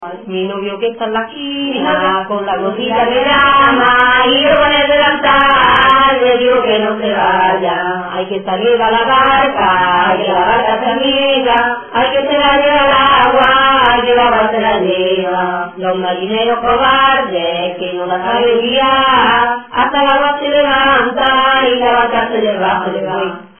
Materia / geográfico / evento: Canciones de corro Icono con lupa
Arenas del Rey (Granada) Icono con lupa
Secciones - Biblioteca de Voces - Cultura oral